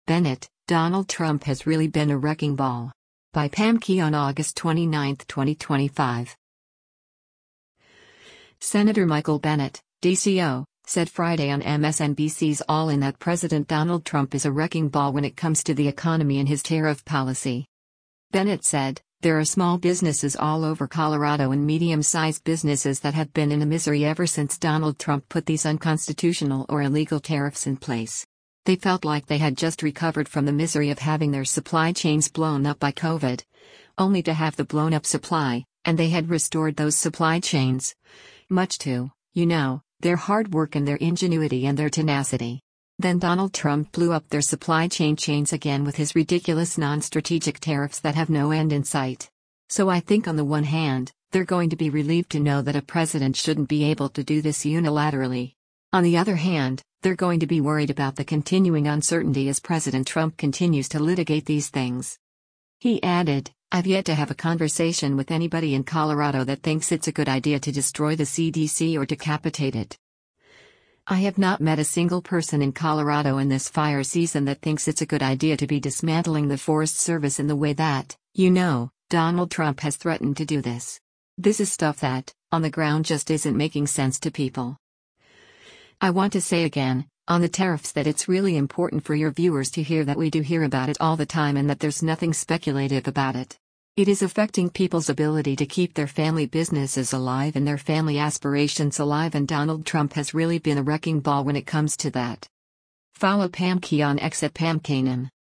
Senator Michael Bennet (D-CO) said Friday on MSNBC’s “All In” that President Donald Trump is a “wrecking ball” when it comes to the economy and his tariff policy.